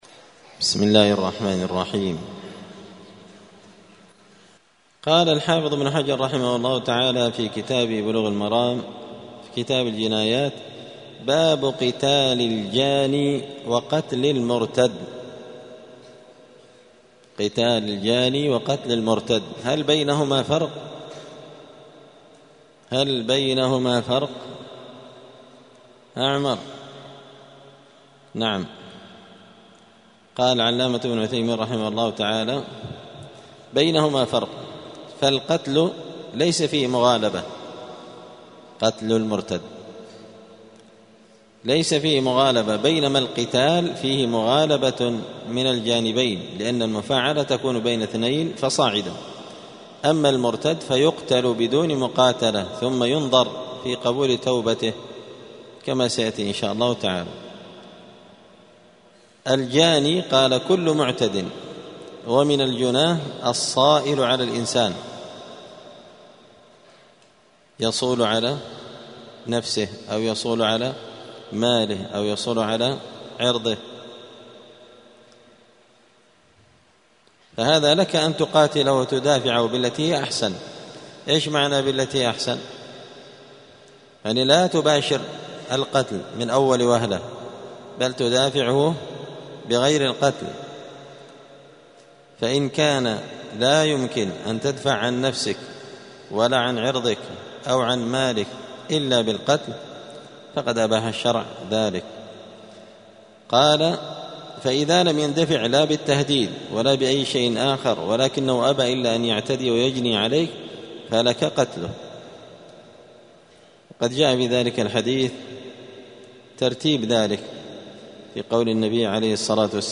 *الدرس الخامس والثلاثون (35) {باب قتال أهل البغي}*